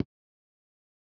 click-short.wav